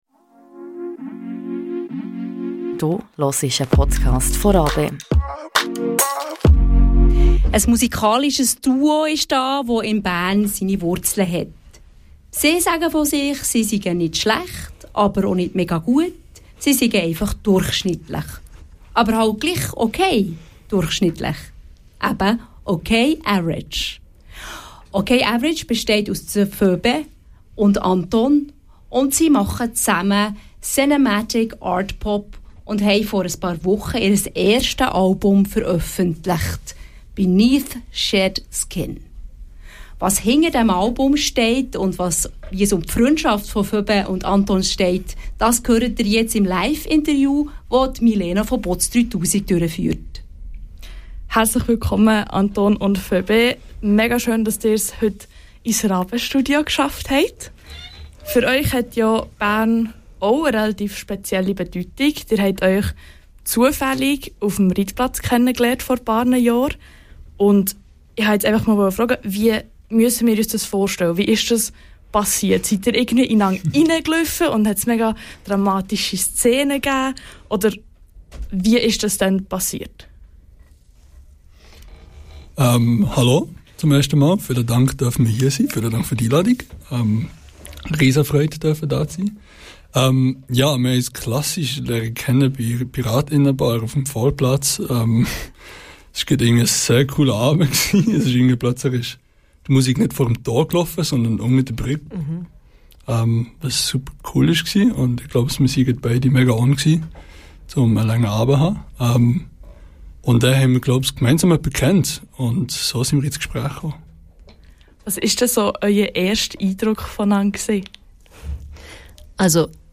Band Ok Average im Interview ~ Radio RaBe Podcast